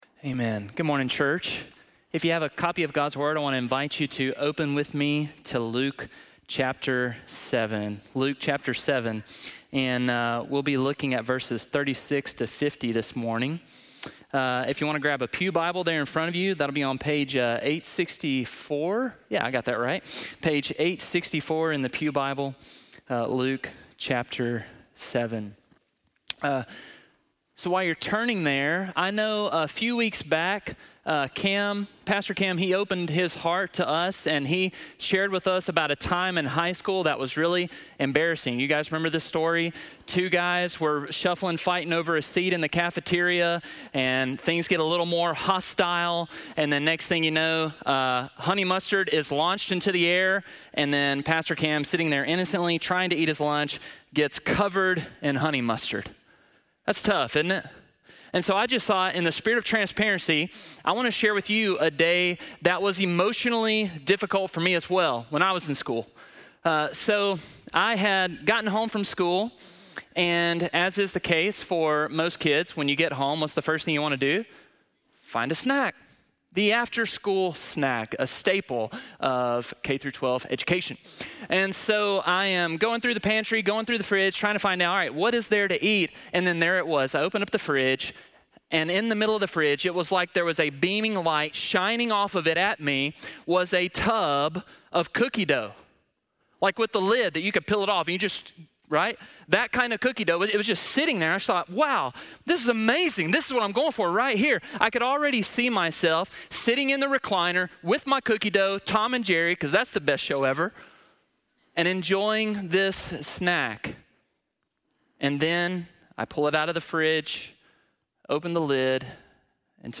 Worship | LaGrange Baptist Church